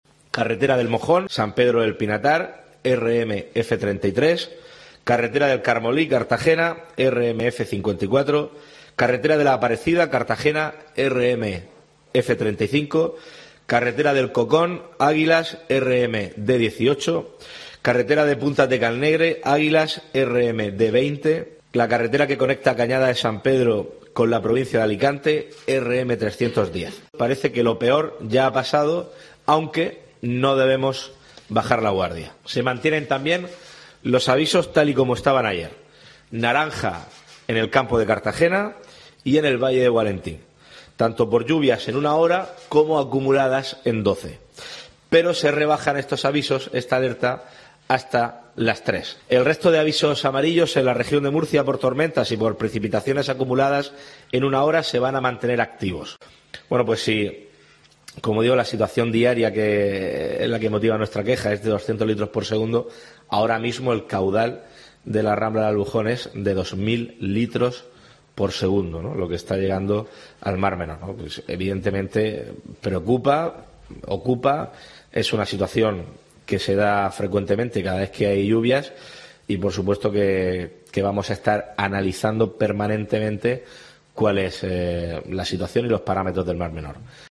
En una comparecencia ante los medios de comunicación desde el Centro de Coordinación de Emergencias, donde ha presidido una reunión de seguimiento del plan contra inundaciones, Fernando López Miras ha subrayado que los "modelos predictivos se han cumplido", especialmente en Cartagena y su comarca, donde se registraron de madrugada más de 100 litros por metro cuadrado.